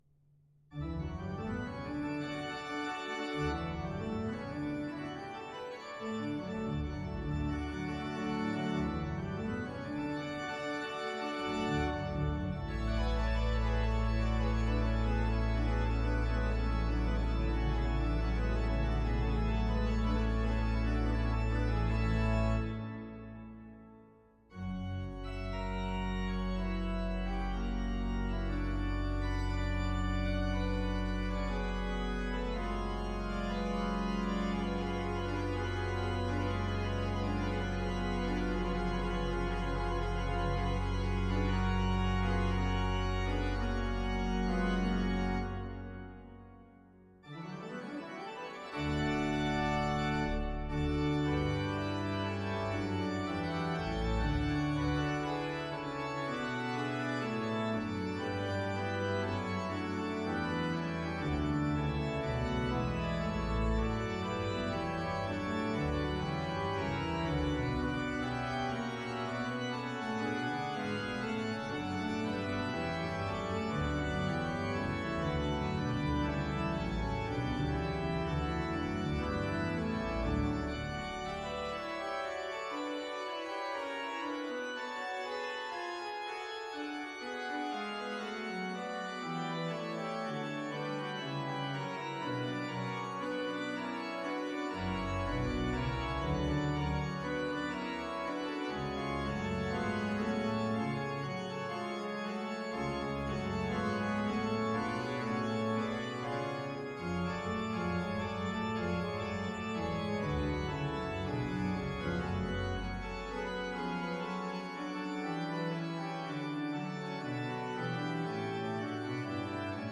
organ17.mp3